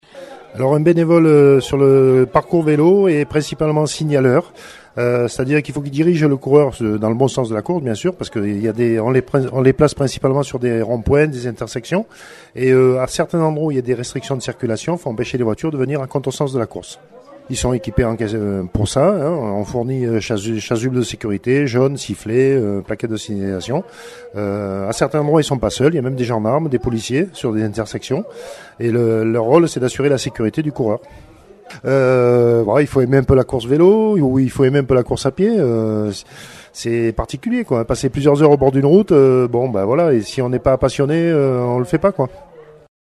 Interviews des tri-athlètes et des entraineurs